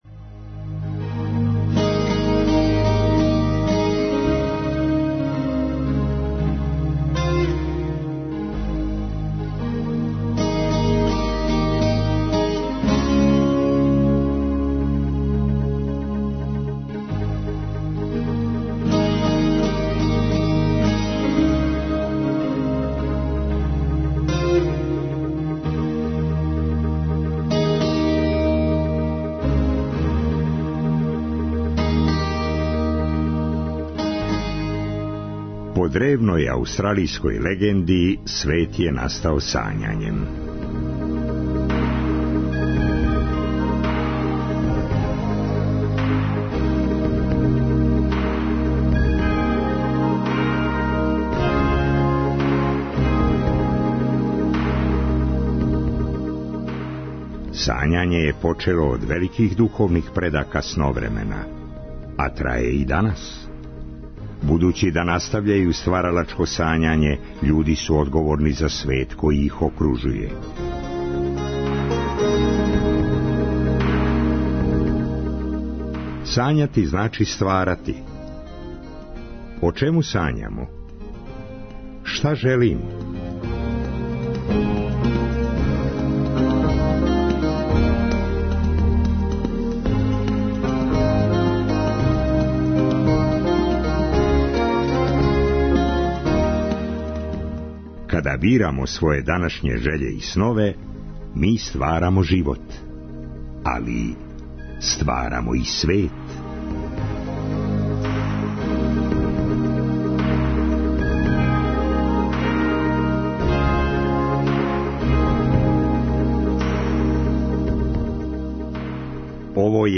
Трећи и четврти сат Сновремена одвајамо за добру музику, уз неке старе и нове песме и приче.